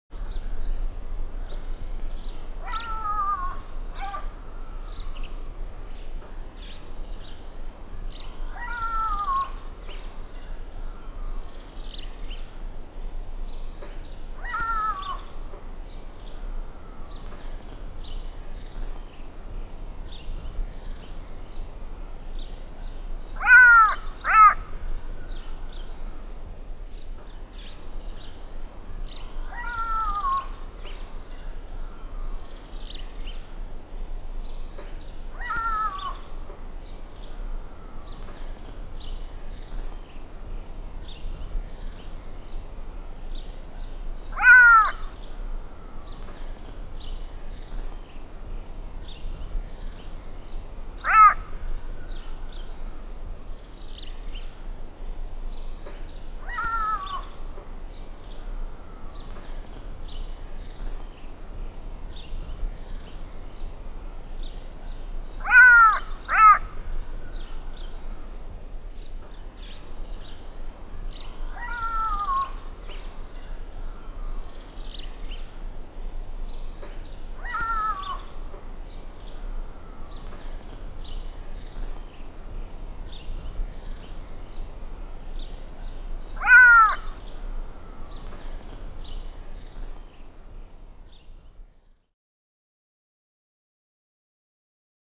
animal
Jackal Distant Barking with Grunts